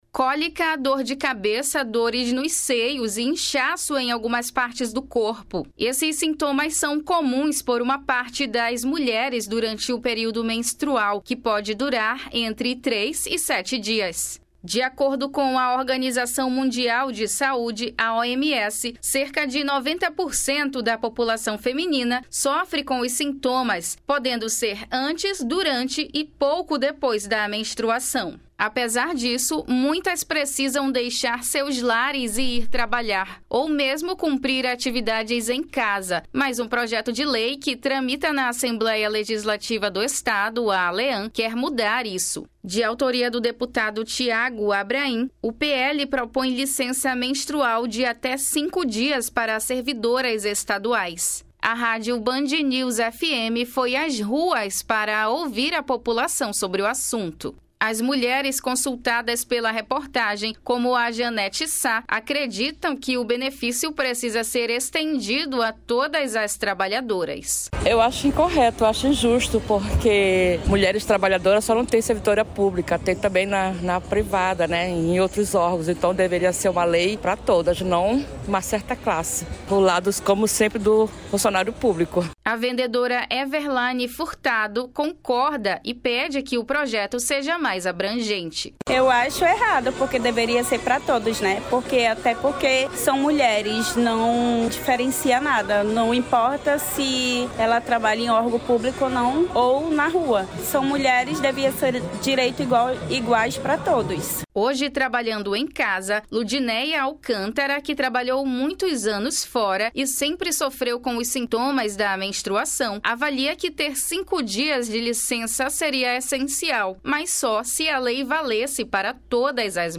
Reportagem
A rádio Band News FM foi às ruas para ouvir a população sobre o assunto.